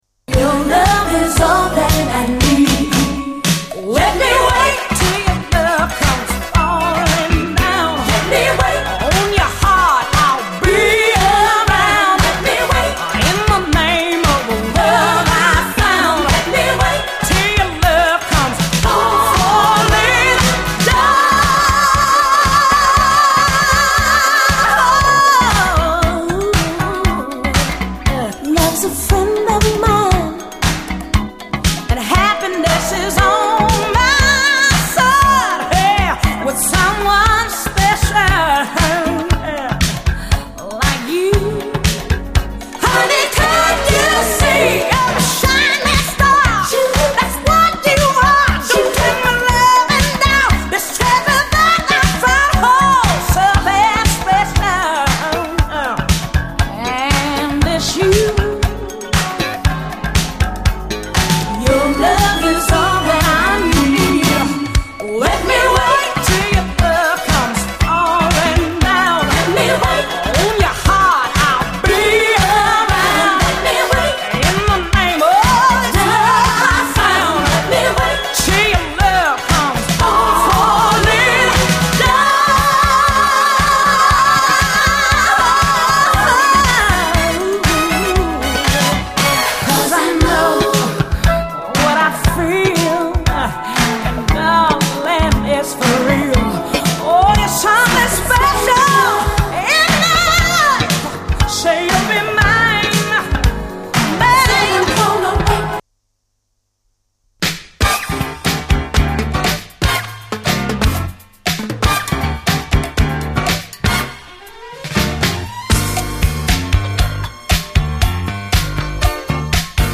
オルガン
こちらもスペイシーでメロウな世界観に酔いしれる、内容最高のレアグルーヴ〜ジャズ・ファンク名盤！
銀河間を交信できそうなくらいに美しくスペイシーなLOFT的トラック！